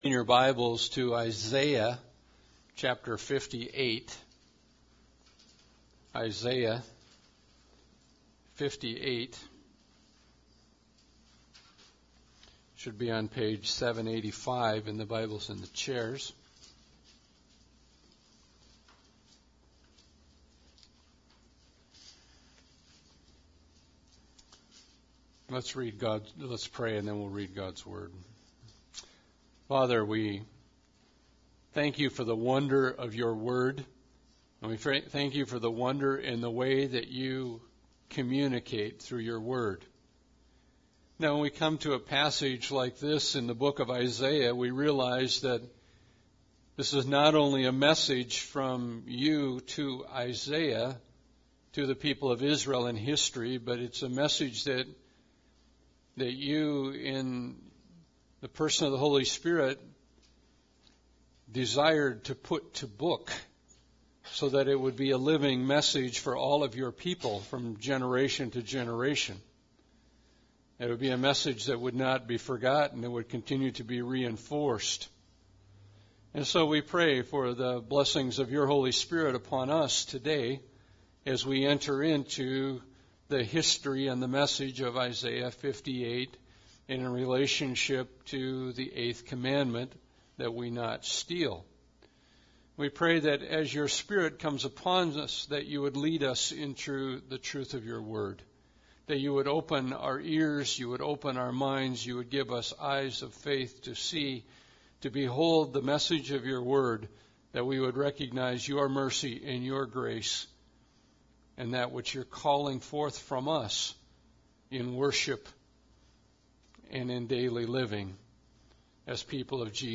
Isaiah 58 Service Type: Sunday Service Bible Text